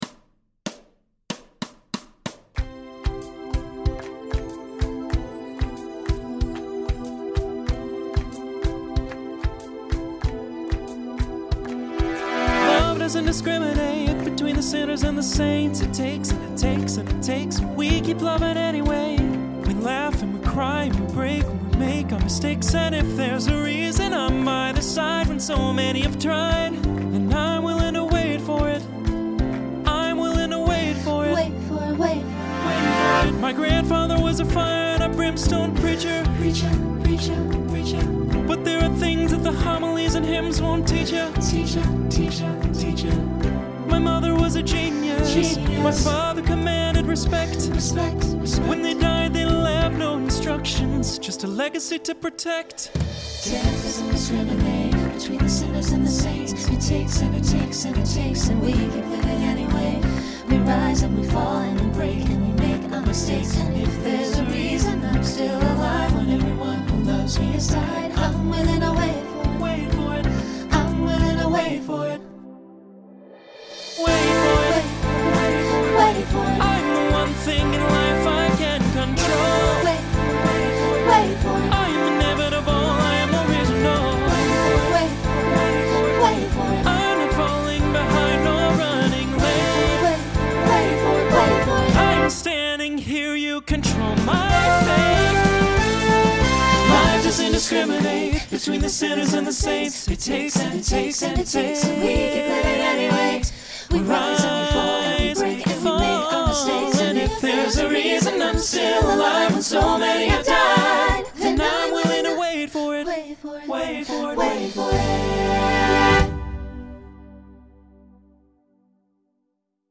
Mid-tempo , Solo Feature Voicing SATB